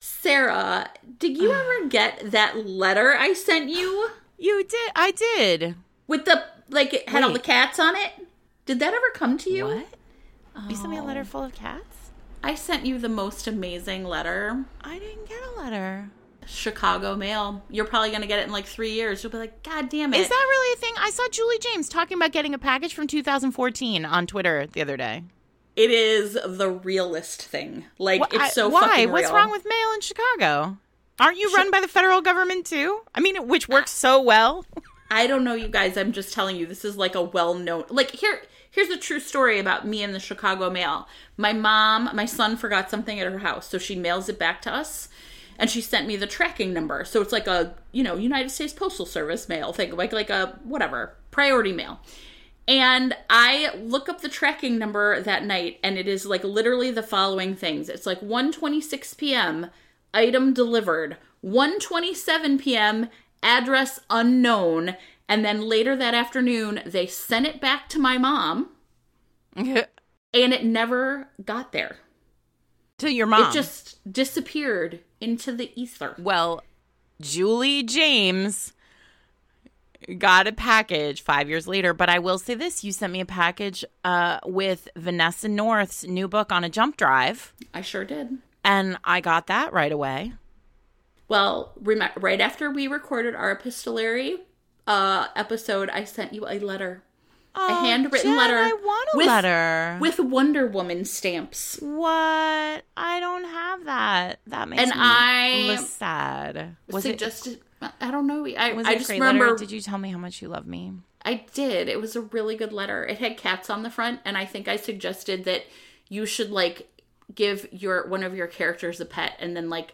We talked about our favorite books with a roomful of librarians & bloggers, and we had the best time.
the-live-episode-final-revc.mp3